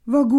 Gravona (sud)